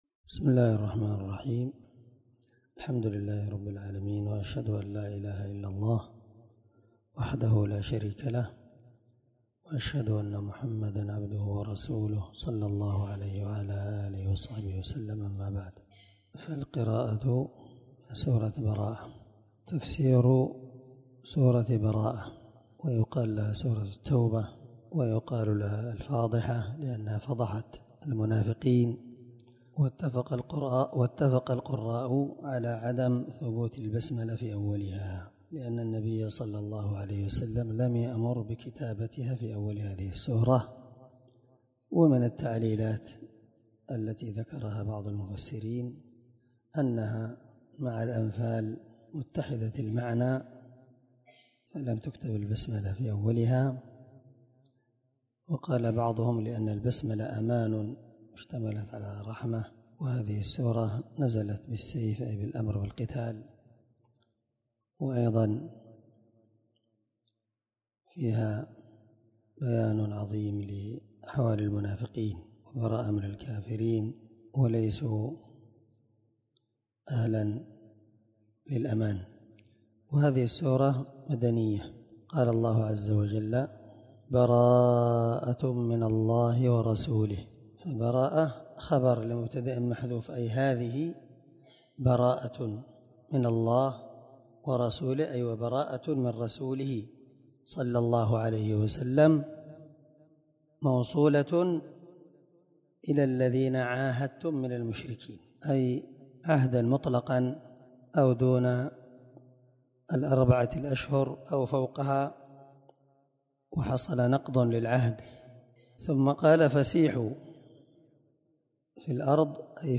529الدرس 1 تفسير آية ( 1 – 4 ) من سورة التوبة من تفسير القران الكريم مع قراءة لتفسير السعدي